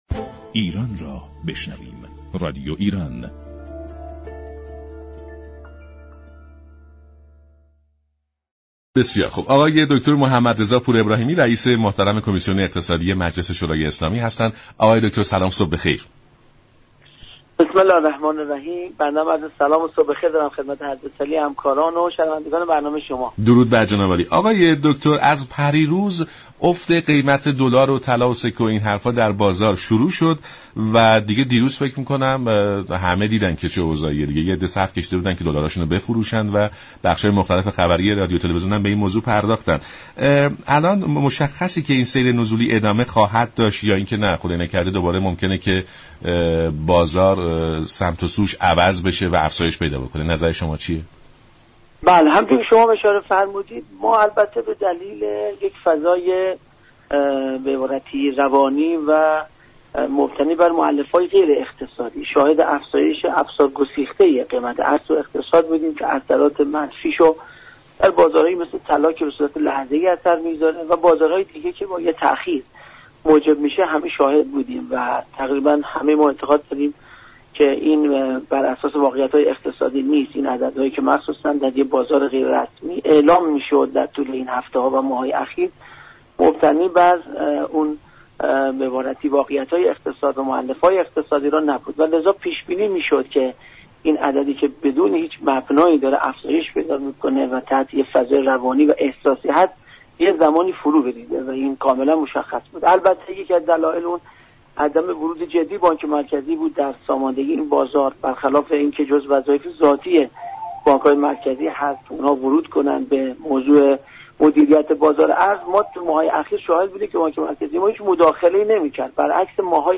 رئیس كمیسیون اقتصادی مجلس شورای اسلامی در برنامه سلام صبح بخیر رادیو ایران گفت : حضور فعال بانك مركزی در این شرایط می تواند تعدیل كننده بازار باشد.